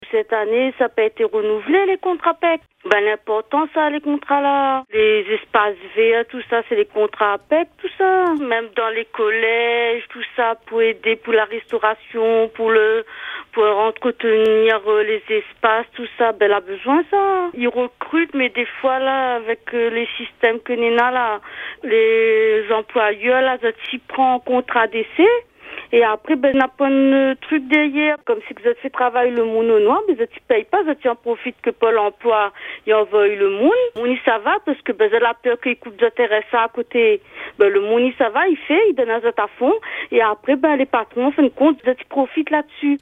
Une auditrice a souhaité réagir à cette décision. Elle rappelle que les contrats PEC jouent un rôle essentiel dans l’insertion professionnelle, en particulier dans des secteurs pourtant en manque de main-d’œuvre.